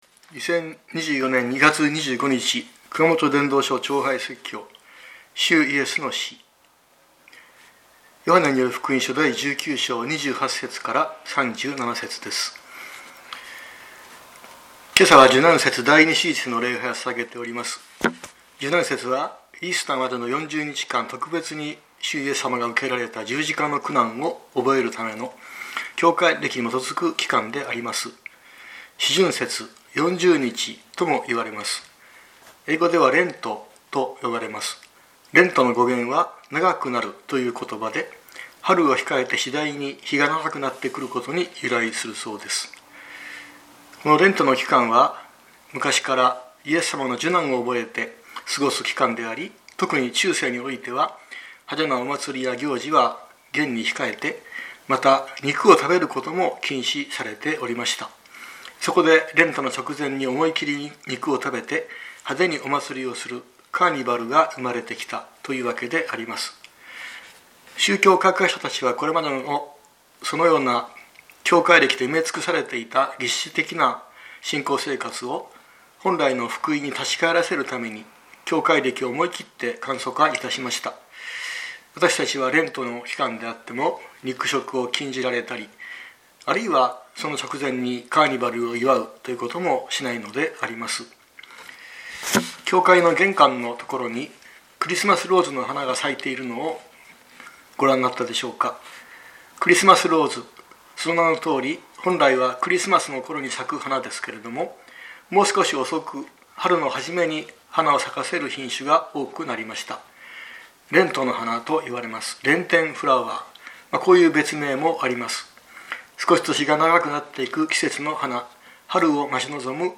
2024年02月25日朝の礼拝「主イエスの死」熊本教会
熊本教会。説教アーカイブ。